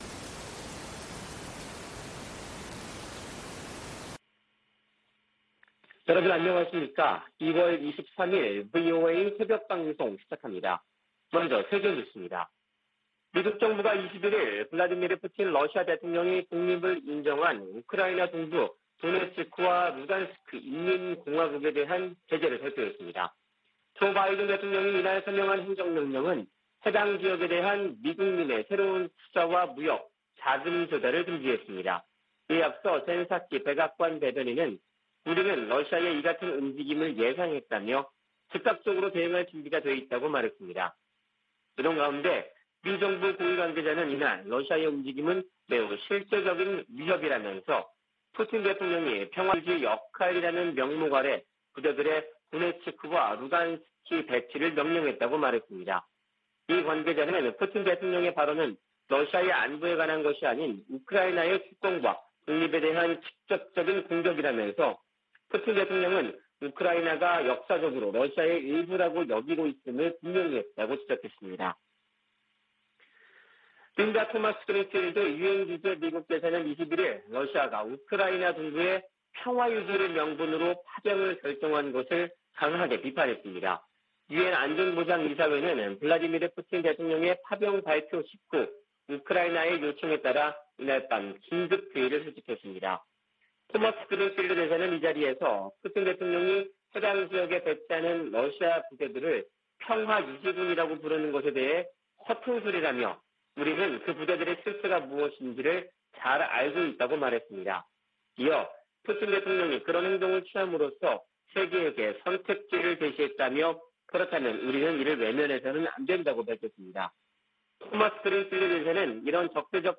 VOA 한국어 '출발 뉴스 쇼', 2021년 2월 23일 방송입니다. 토니 블링컨 미 국무장관이 왕이 중국 외교부장과 북한 문제와 우크라이나 사태 등에 관해 전화협의했습니다.